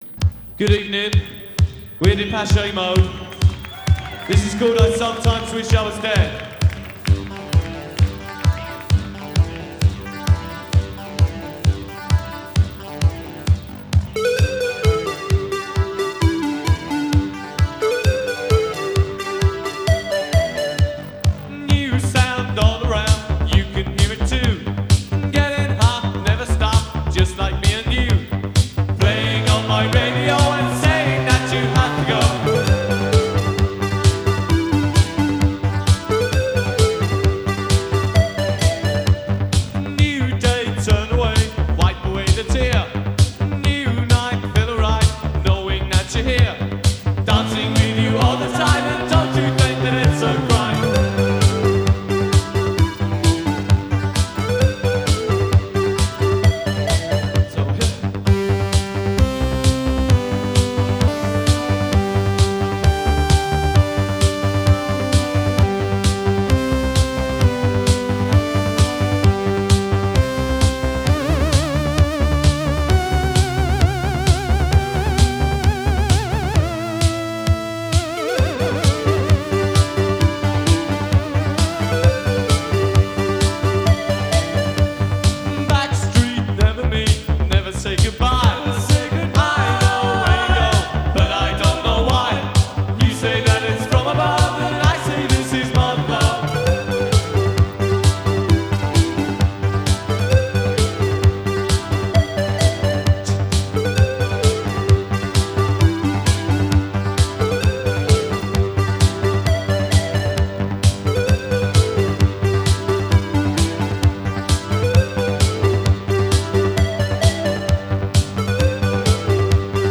in concert at Lantaren, Rotterdam